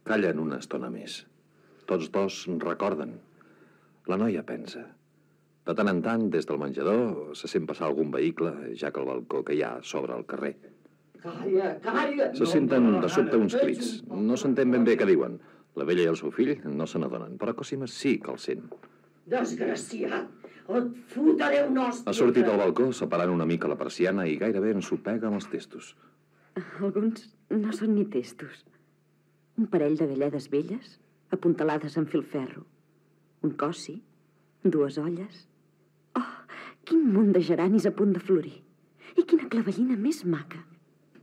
Fragment de l'adaptació radiofònica de l'obra "El carreró contra Còssima" (1969) de Joaquim Carbó
Ficció